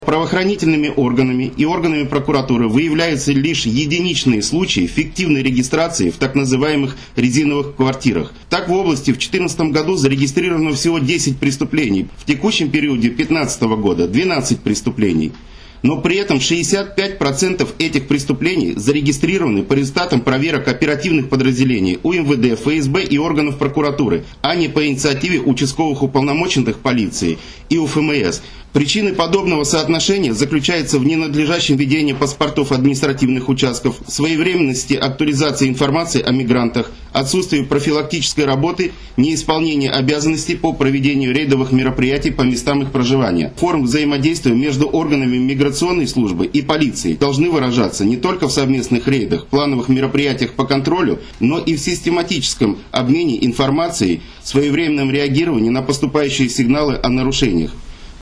Исполнение требований миграционного законодательства стало центральной темой координационного совещания правоохранительных органов региона, которое состоялось 24 сентября.